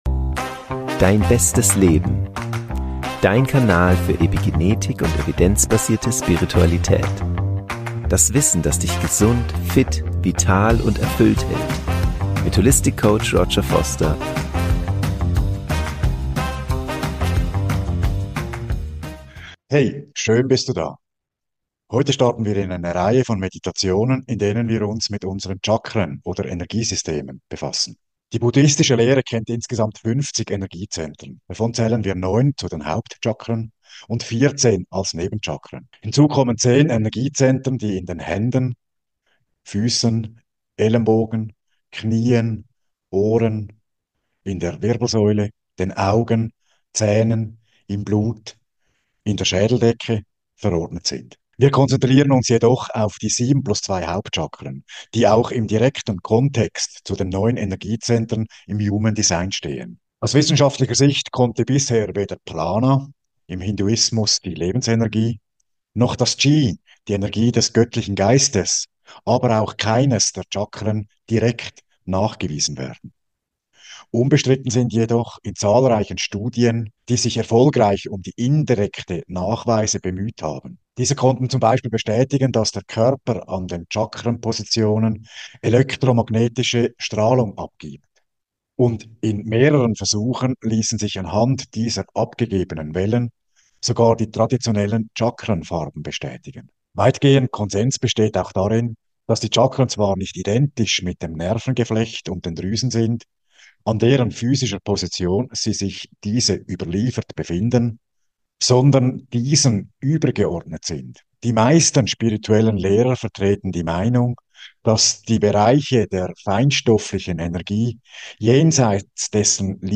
In diesem Video erhältst Du eine Meditation zur Aktivierung Deiner 7 Hauptchakren. Die heilende Frequenz von 1024 Hz versetzt Dich in einen anderen Bewusstseinszustand, in dem Du durch farbliche Visualisierung besonders leicht und effektiv Deine Chakren aktivieren kannst.